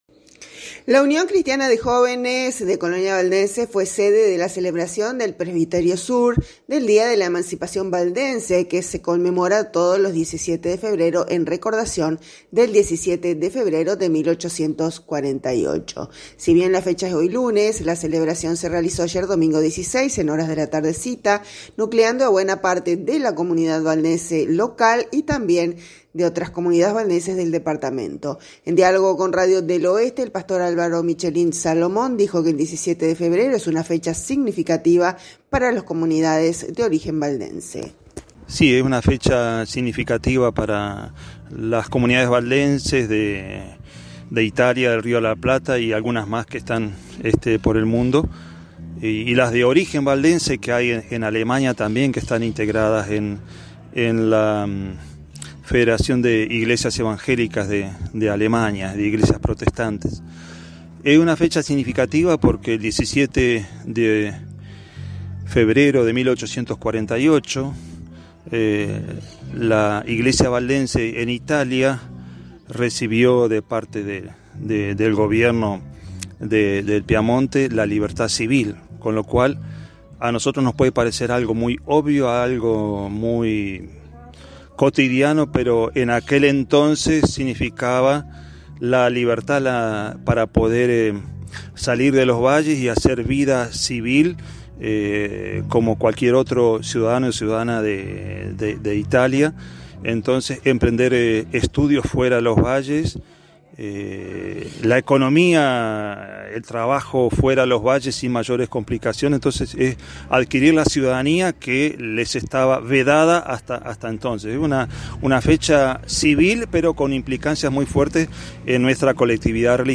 En diálogo con Radio del Oeste